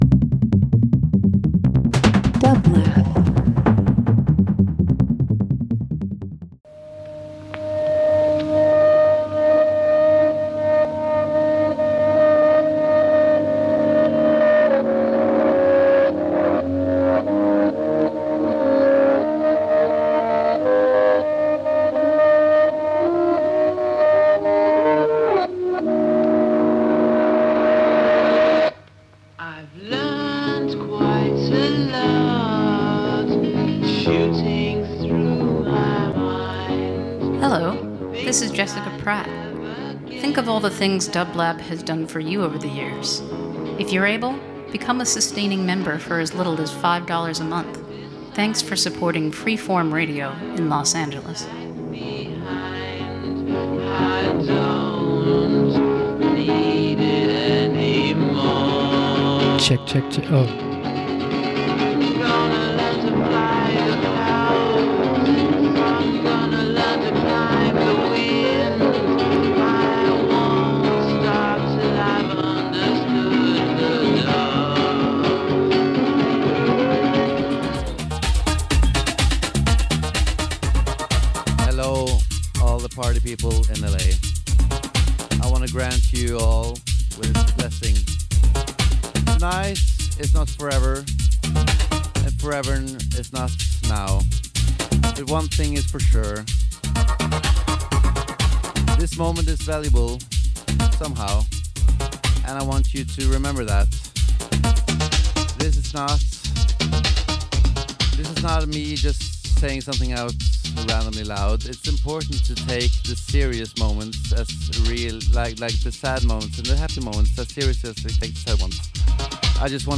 Cumbia Dance Latin